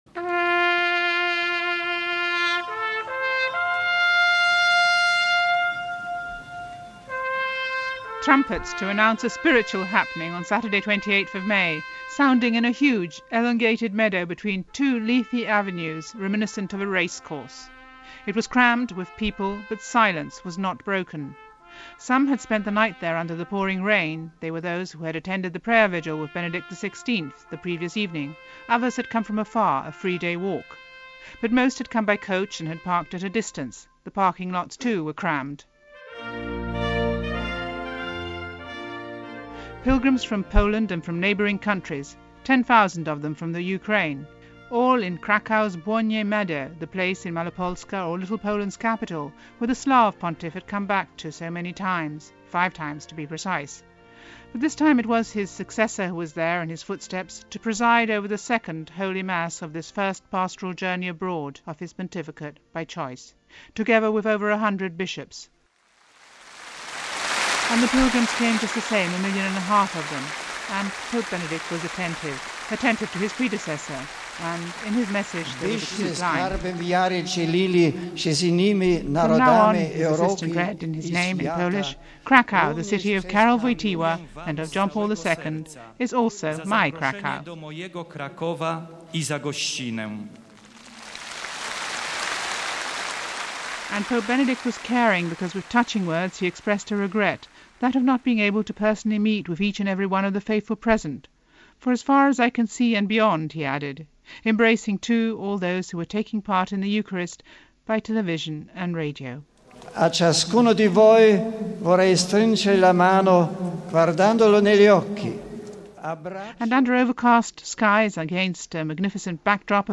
He began the day with some 900,000 Poles who sang, clapped and chanted «Benedetto, Benedetto» at Mass in a soggy field. Pope Benedict urged them to share their faith with other countries in an increasingly secular Europe.